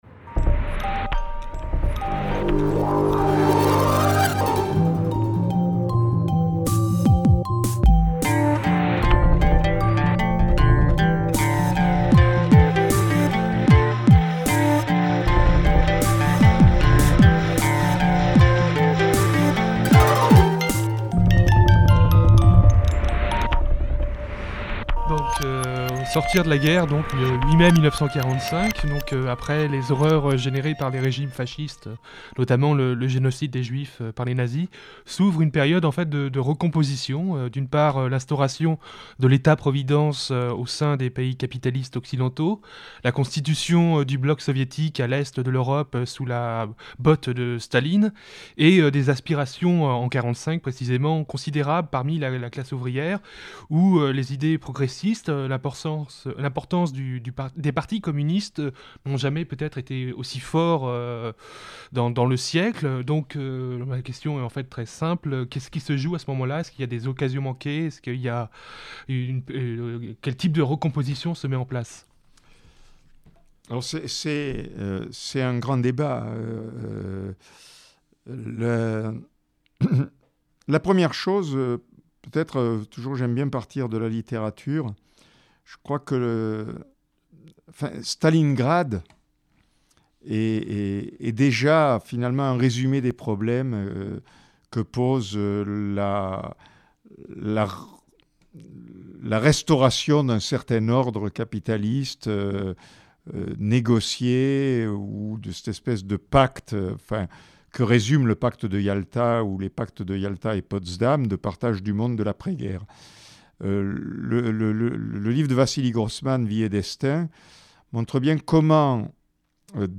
Entretiens radiophoniques avec Daniel Bensaïd
Au départ il s’agit d’un projet radiophonique, diffusé sur Fréquence Paris Plurielle (106,3 FM).